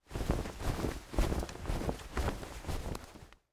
Cloth Flap Jacket Sound
household
Cloth Flap Jacket